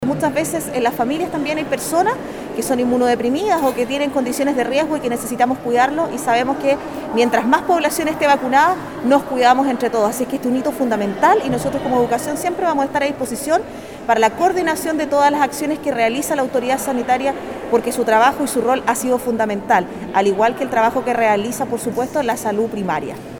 Dijo también la seremi de Educación Paulina Lobos que siempre habrá disposición de parte del área educativa para atender los llamados de la autoridad sanitaria.